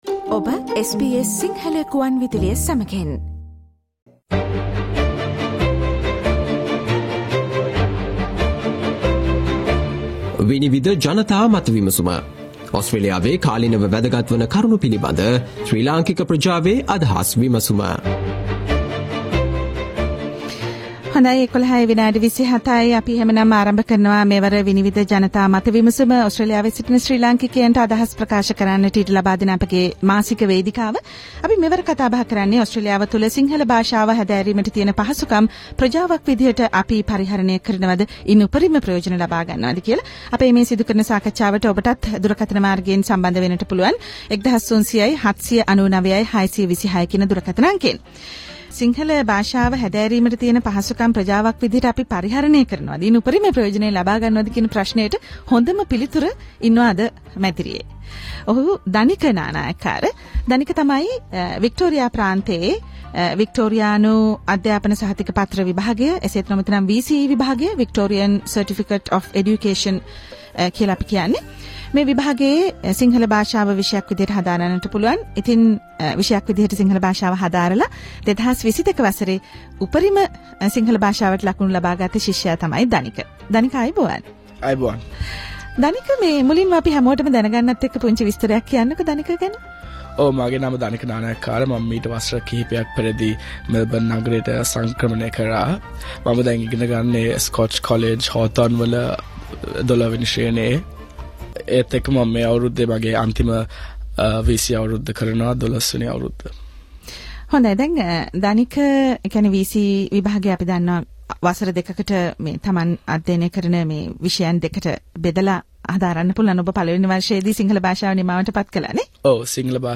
Vinivida 32:34 SBS Sinhala monthly panel discussion -"Vinivida" on January 2023.
in the SBS radio studios